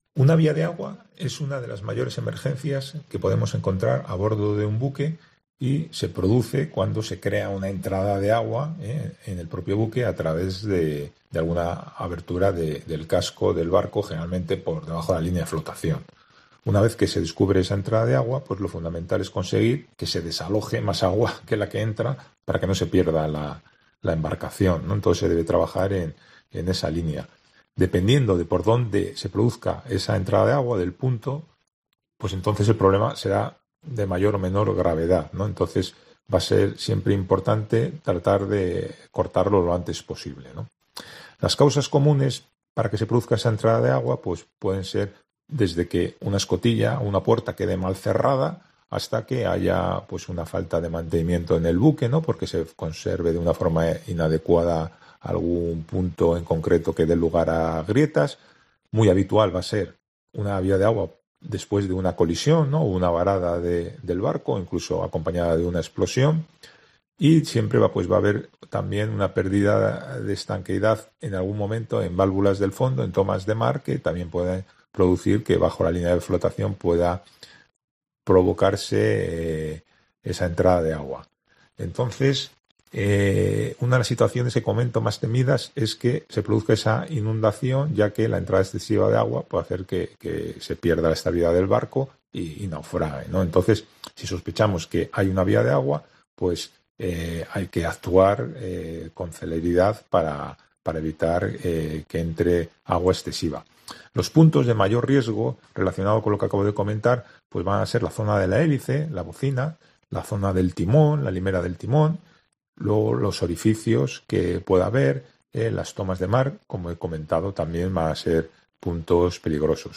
¿Qué es una vía de agua? ¿cuáles pueden ser las causas? preguntamos a un ingeniero naval